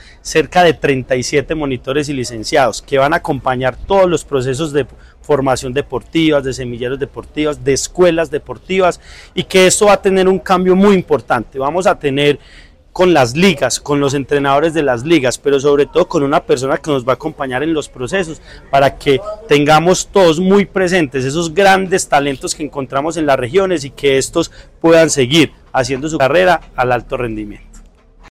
Andrés Duque Osorio, secretario de Deporte, Recreación y Actividad Física de Caldas.
secretario-de-deporte-de-caldas-andres-duque-osorio-.mp3